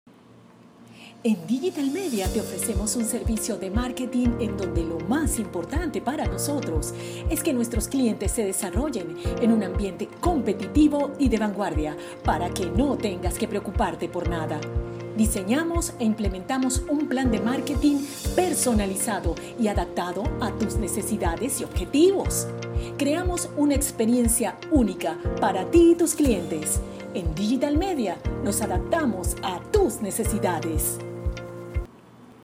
Locutora y actriz de doblaje
kastilisch
Sprechprobe: eLearning (Muttersprache):
voice actress voice actress with versatile voice and experience in voice characterization